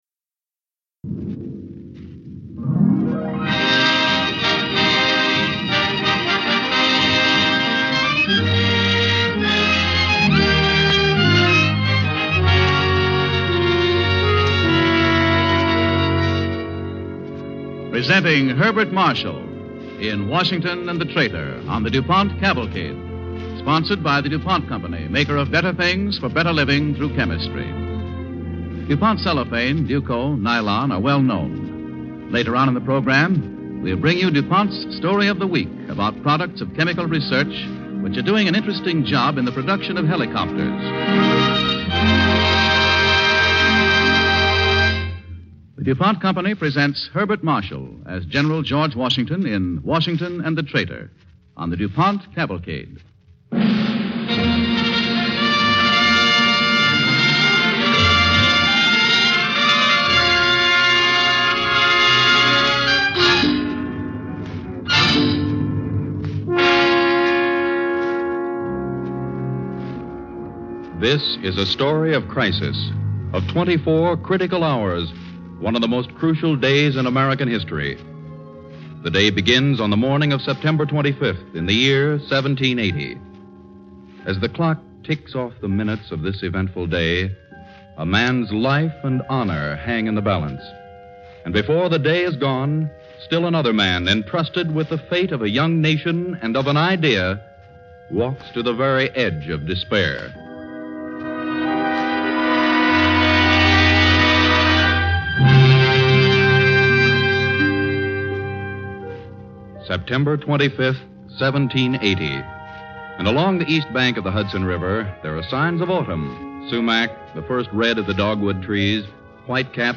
Washington and the Traitor, starring Herbert Marshall with host by Walter Houston